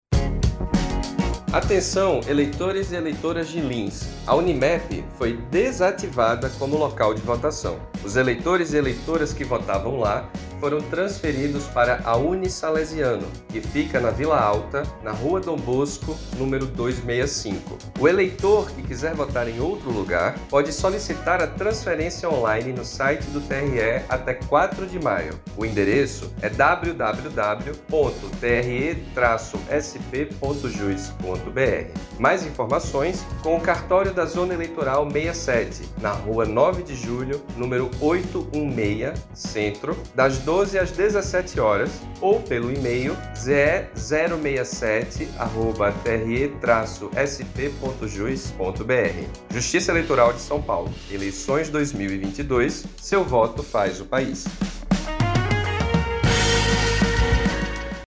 spot lins_mixagem II.mp3.mp3